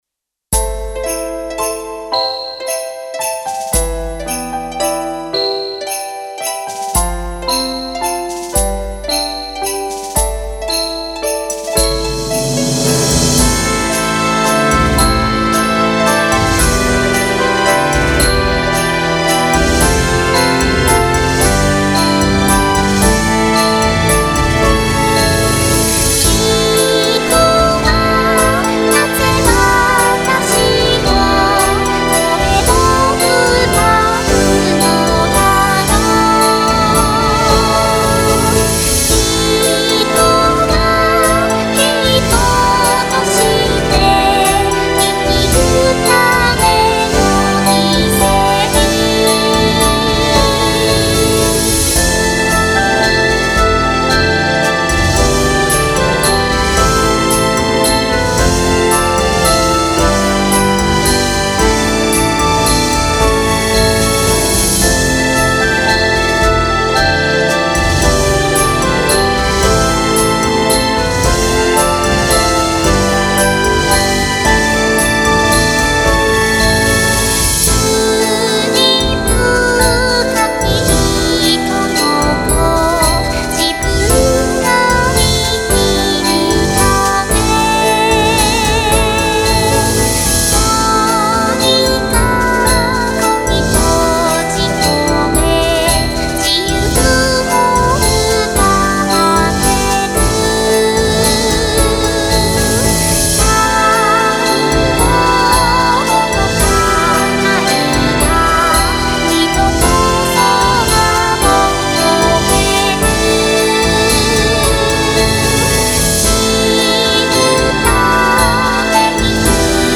使用ボーカロイド